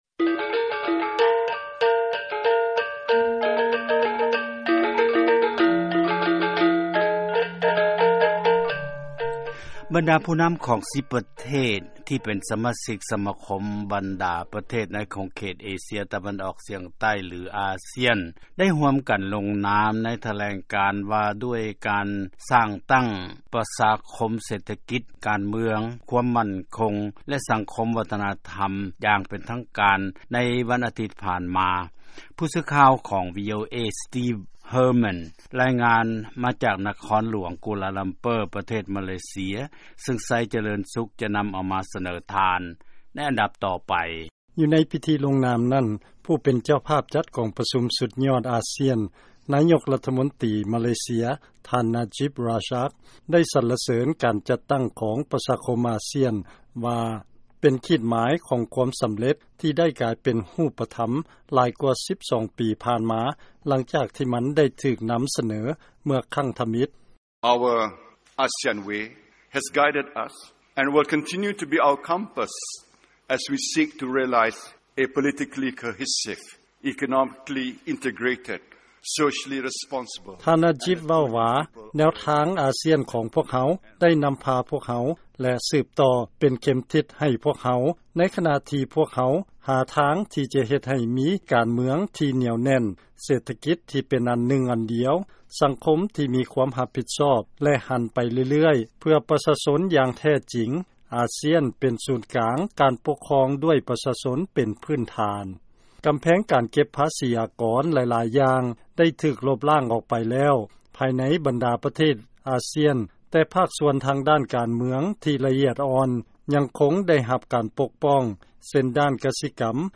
ຟັງລາຍງານ ອາຊຽນ ສ້າງຕັ້ງ 'ປະຊາຄົມ' ທີ່ມີປະຊາກອນ ລວມກັນ ຫຼາຍກວ່າ 600 ລ້ານຄົນ.